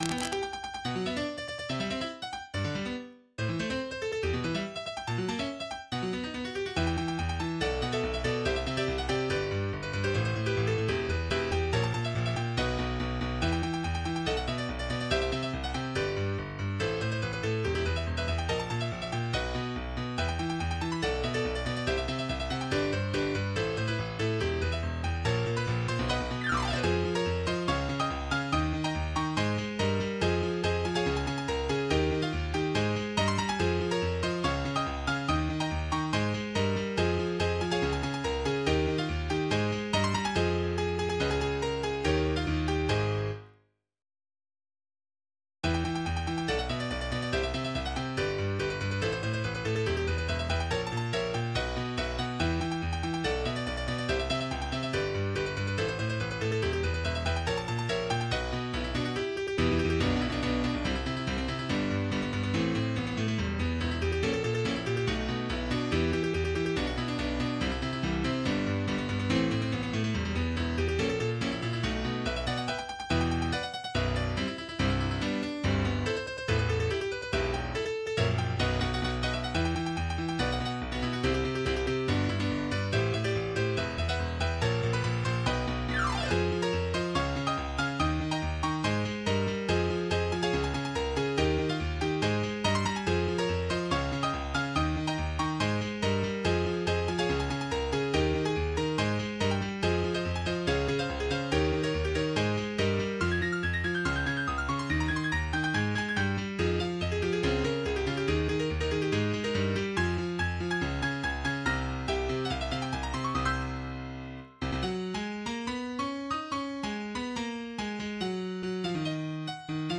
MIDI 19.65 KB MP3 (Converted) 2.45 MB MIDI-XML Sheet Music